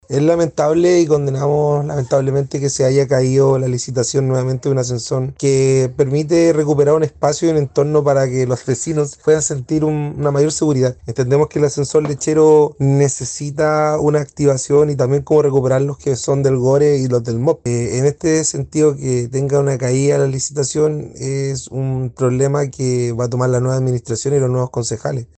Por parte del municipio de Valparaíso, el concejal Gonzalo García se refirió al tema.